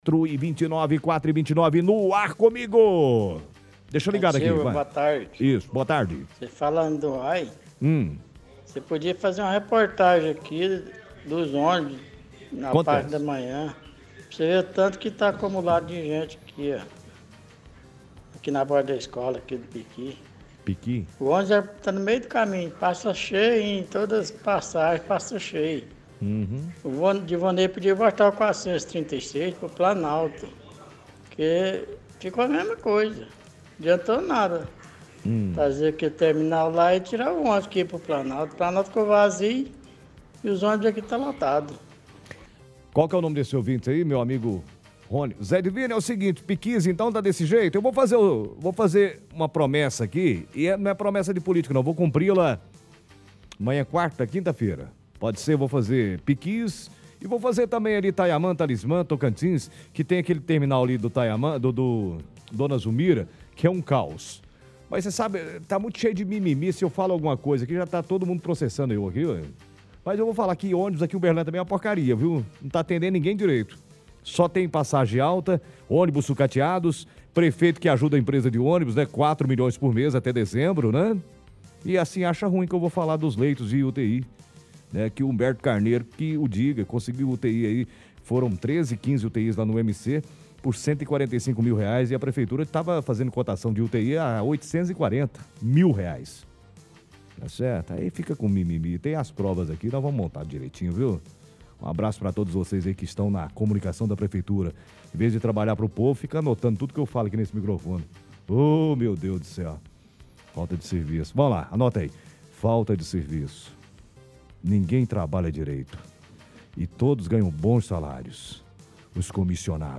Ligação Ouvintes – Denúncia Transporte/Denúncia Dengue e Zika